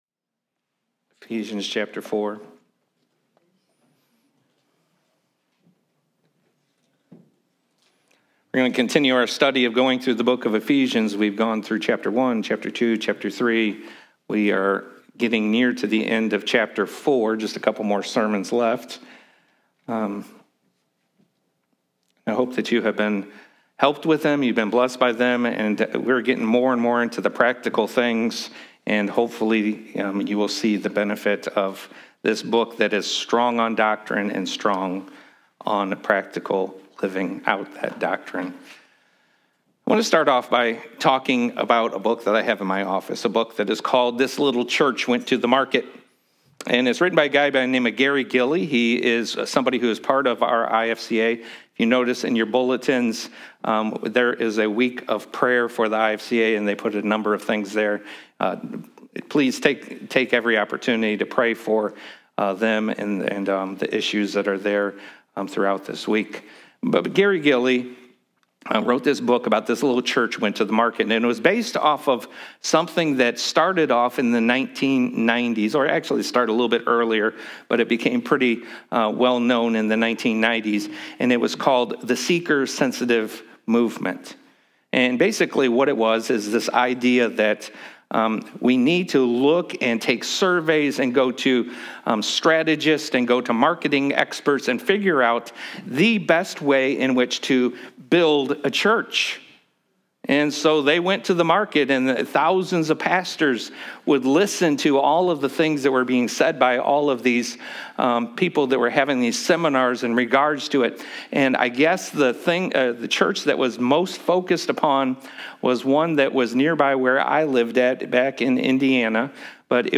Series: Epistle to the Ephesians, TBC Morning Service
TBC-Morning-Service-4.30.23.m4a